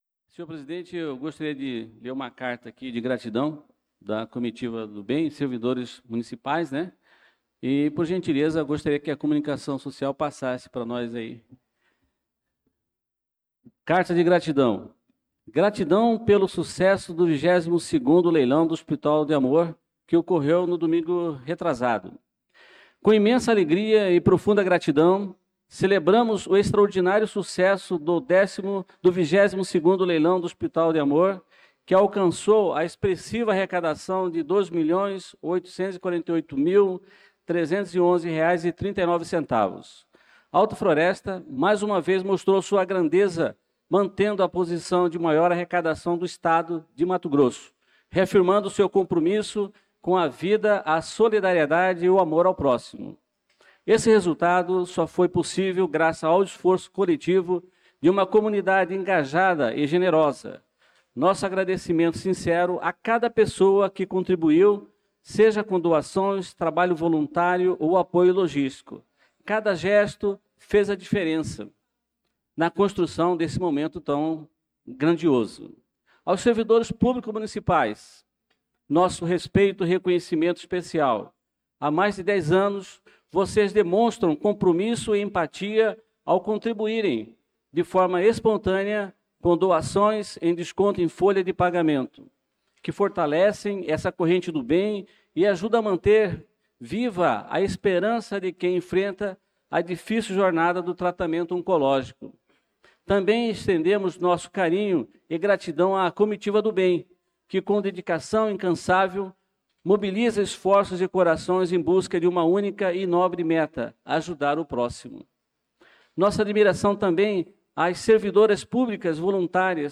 Pronunciamento do vereador Adelson Servidor na Sessão Ordinária do dia 04/08/2025.